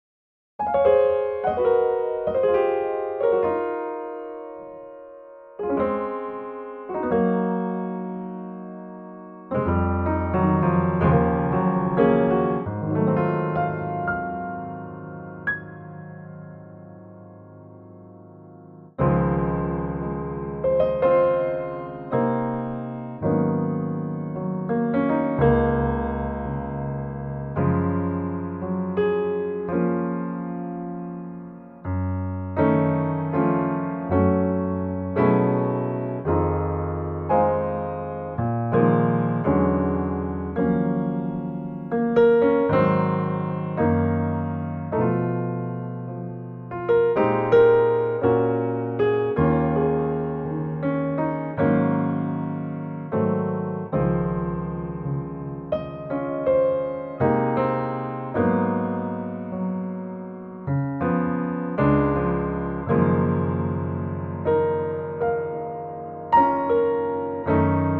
key - Db - vocal range - Ab to Eb
Gorgeous piano only arrangement